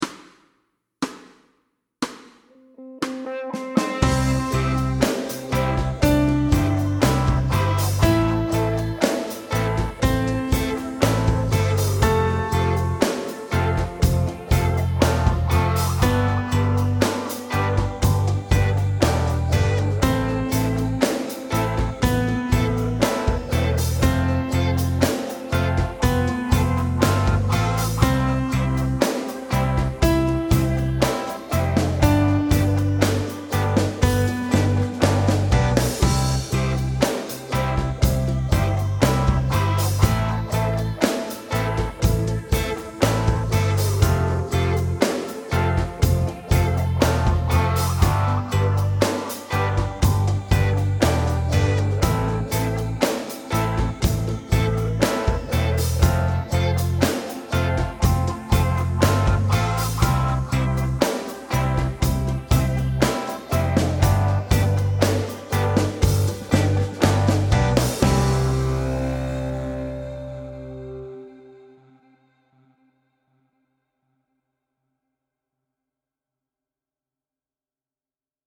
Slow C instr (demo)